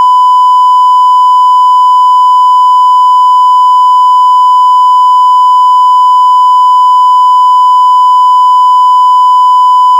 sine-triangle-uint8-audition.wav